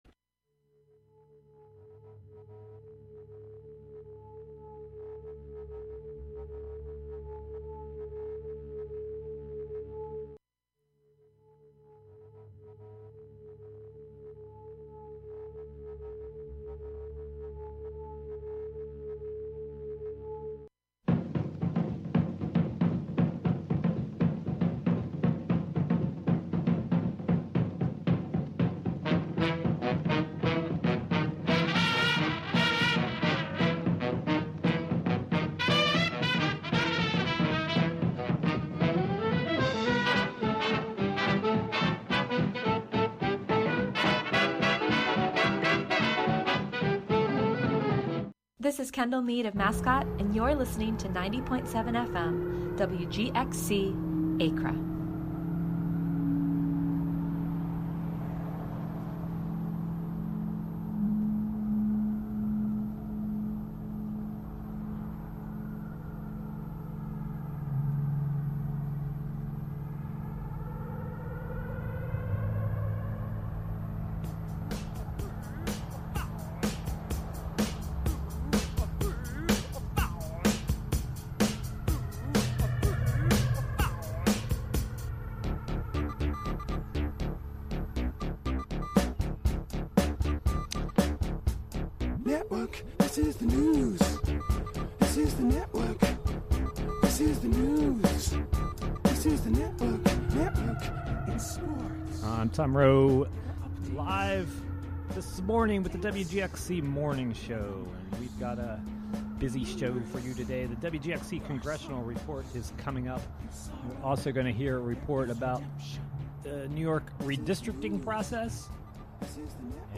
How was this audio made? The show is a place for a community conversation about issues, with music, and more.